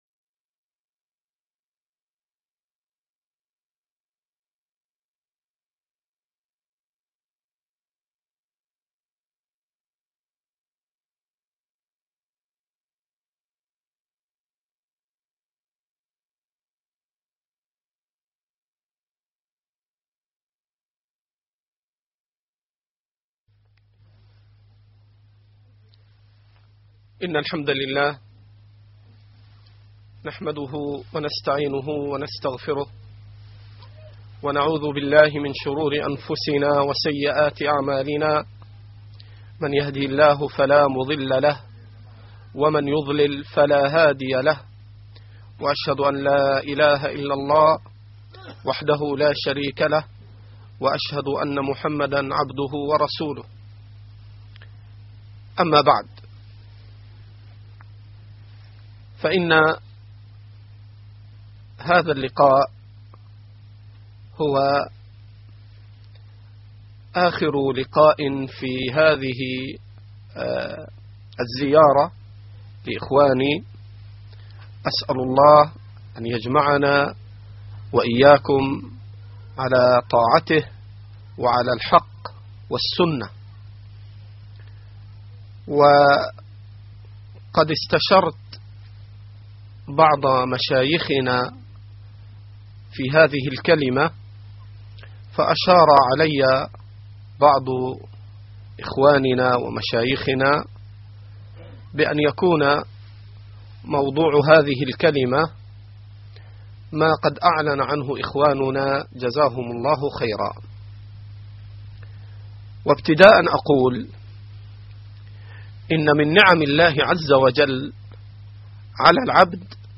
الدروس المنقولة عبر إذاعة النهج الواضح القسم العلمي: التفسير